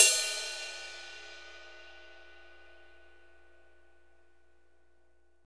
Index of /90_sSampleCDs/Northstar - Drumscapes Roland/DRM_R&B Groove/CYM_R&B Cymbalsx